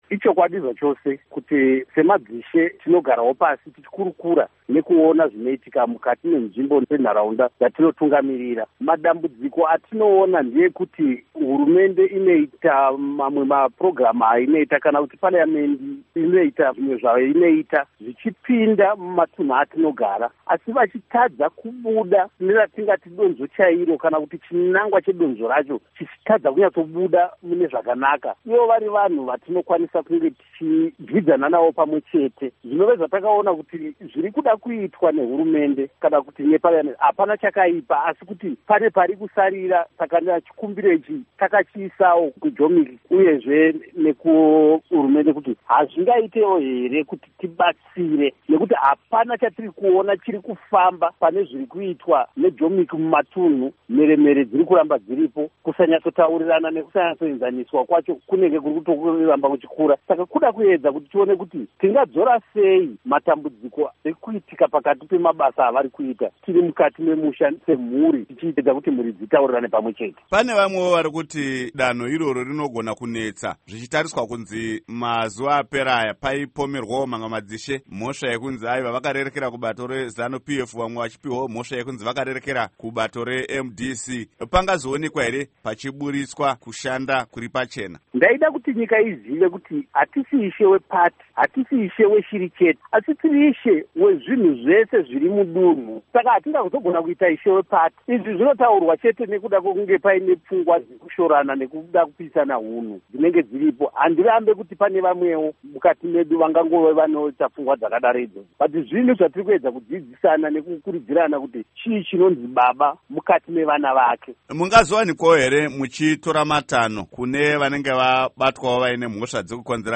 Hurukuro naIshe Edson Chihota